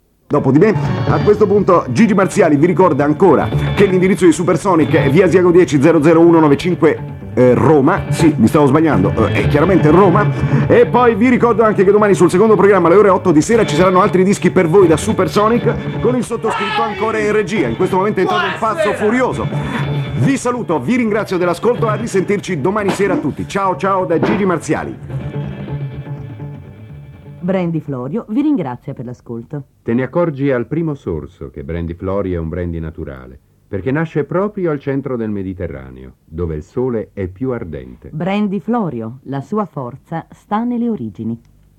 I brani musicali sono sfumati per le solite esigenze di tutela del copyright.
E' incluso anche il commerciale dello sponsor del programma, il dimenticato Brandy Florio ("la sua forza sta nelle origini").